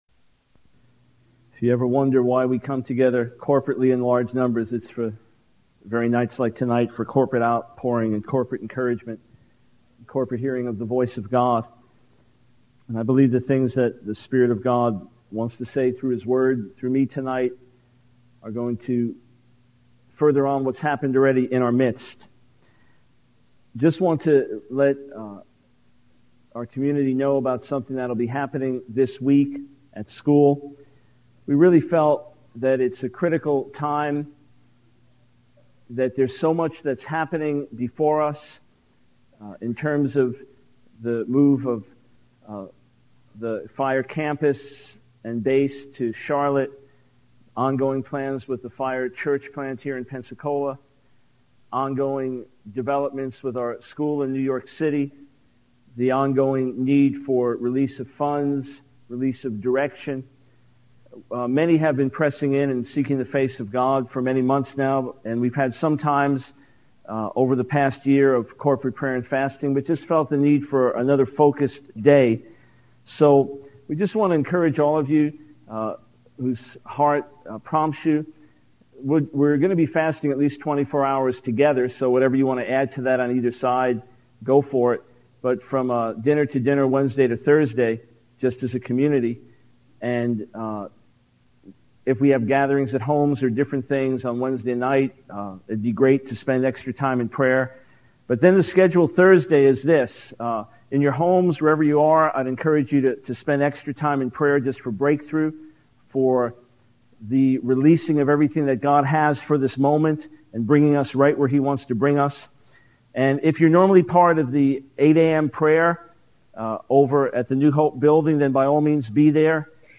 In this sermon, the speaker emphasizes the importance of coming together as a corporate body to experience the outpouring of God's presence and encouragement. They also highlight the need for believers, especially those living in luxurious and materialistic societies, to hold onto eternal truths and not be consumed by earthly possessions.